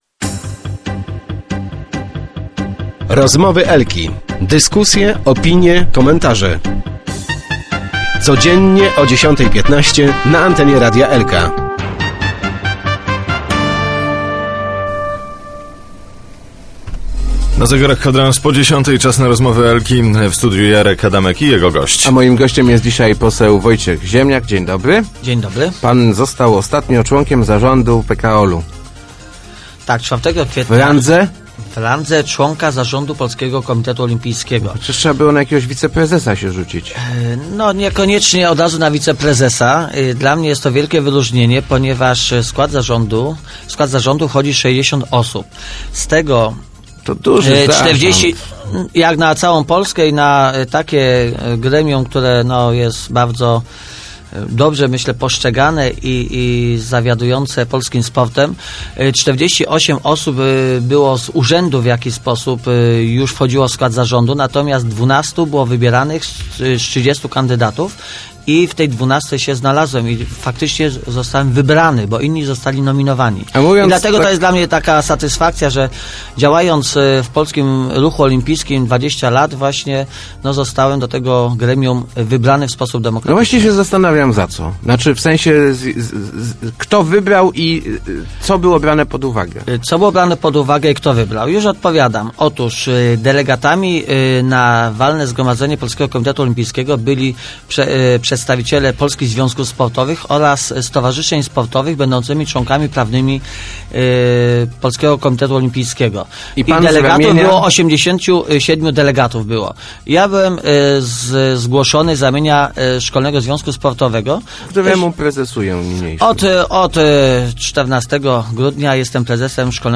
Wybór do zarządu Polskiego Komitetu Olimpijskiego to wielka satysfakcja – mówił w Rozmowach Elki poseł Wojciech Ziemniak. Odpierał też zarzuty odpowiedzialności komitetu za słabe ostatnio występy Polaków na igrzyskach.